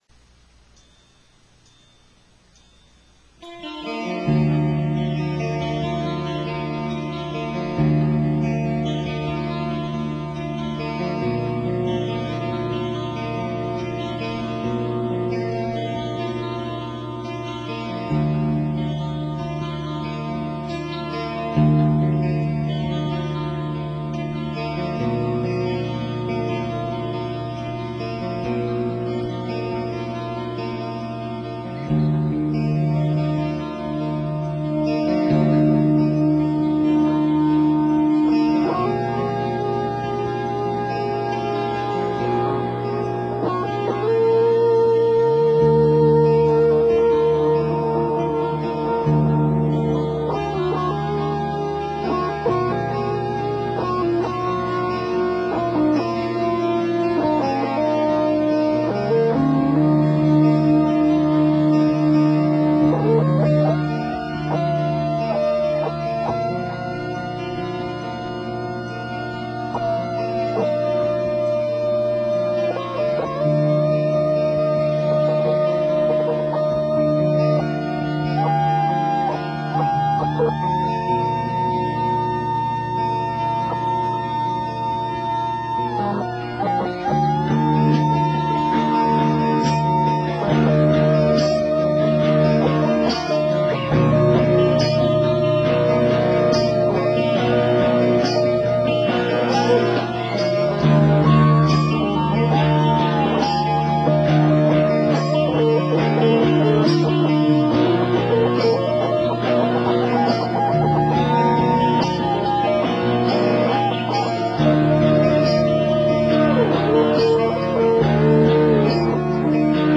でもやっぱこういう曲でもぼくは弾きまくってしまうわけですね(笑)